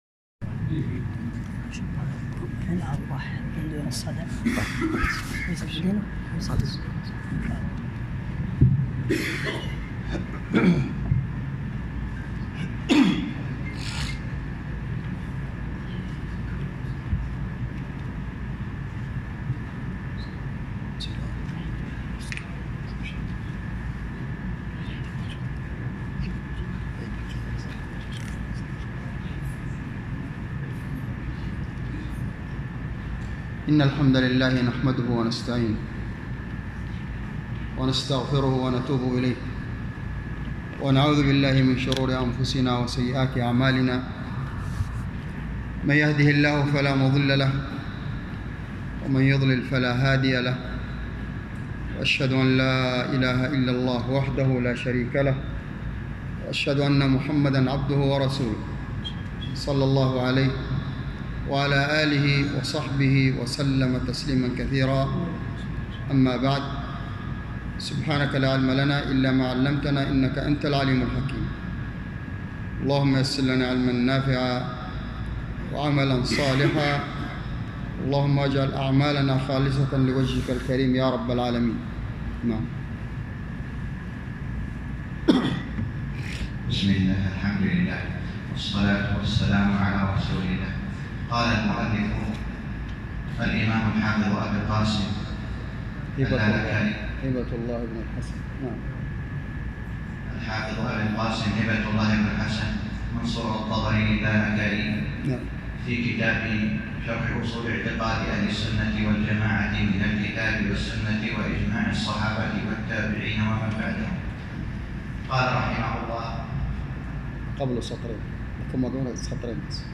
الدرس الرابع - شرح أصول اعتقاد اهل السنة والجماعة الامام الحافظ اللالكائي _ 4